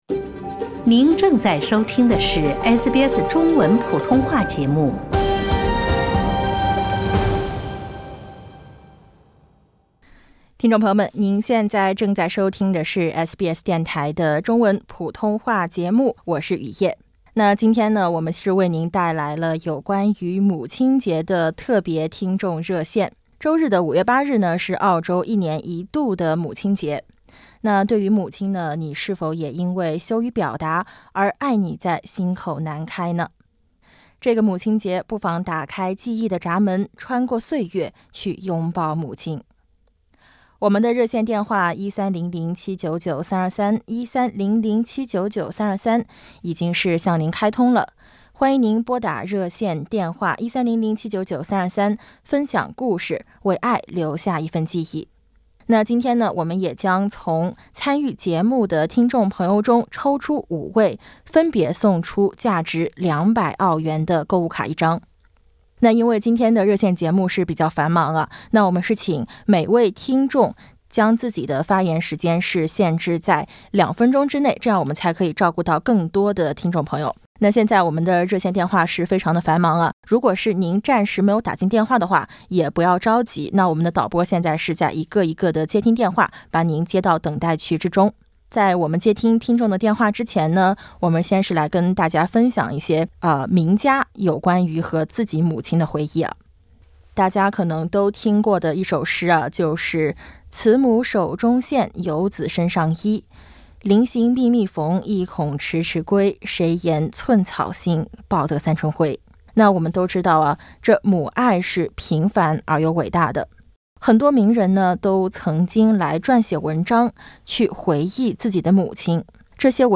【母亲节特别听众热线】我和母亲间的难忘回忆